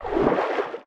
Sfx_creature_trivalve_swim_slow_03.ogg